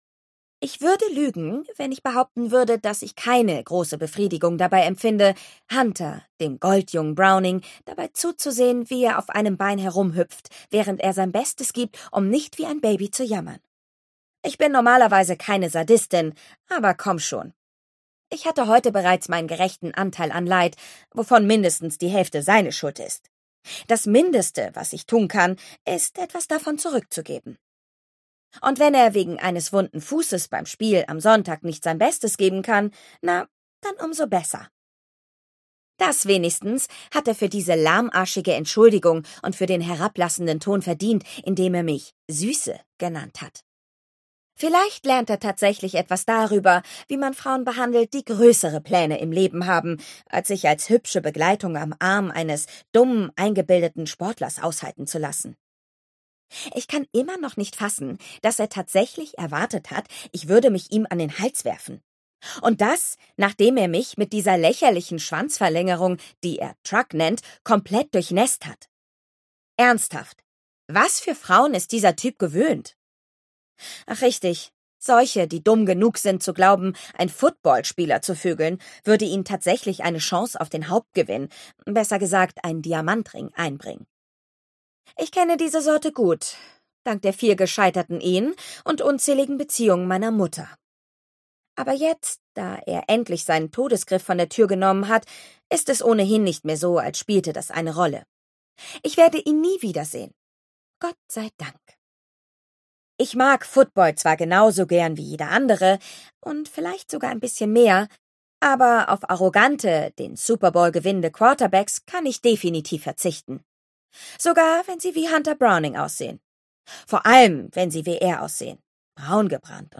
Ausgabe: Ungekürzte Lesung, Hörbuch Download